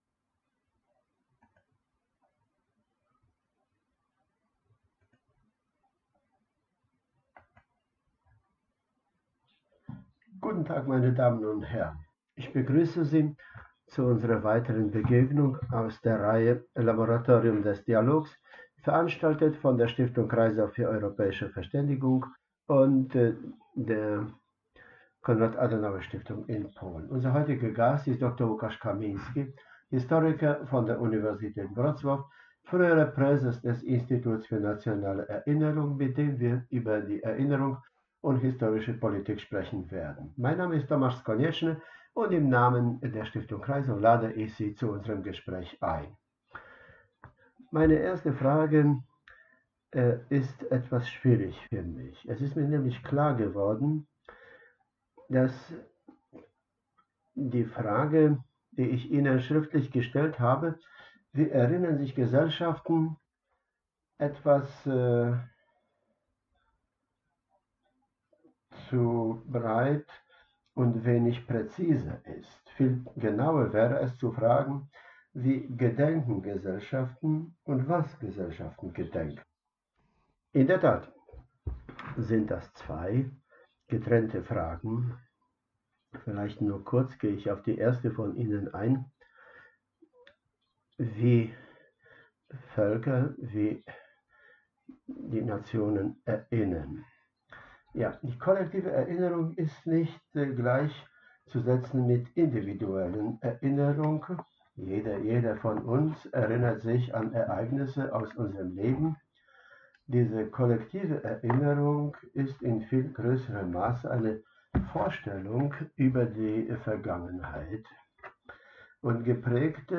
* Das Gespräch wurde auf Polnisch geführt und für den Podcast ins Deutsche übersetzt.